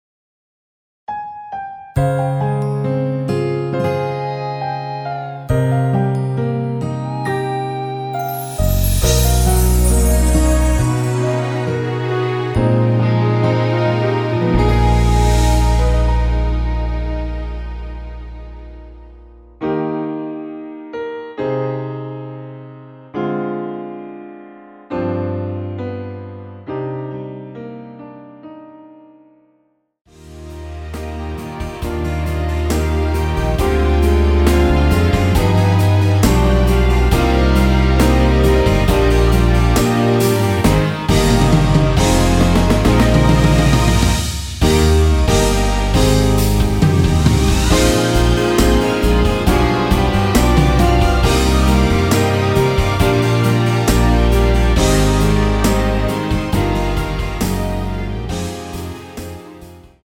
Eb
노래방mr보다더고급지네요
앞부분30초, 뒷부분30초씩 편집해서 올려 드리고 있습니다.
중간에 음이 끈어지고 다시 나오는 이유는